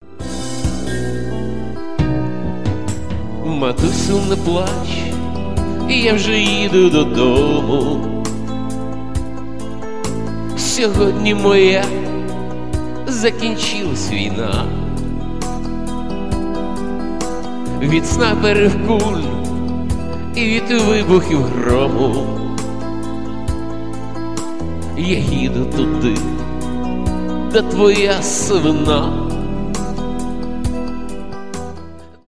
шансон , грустные
авторская песня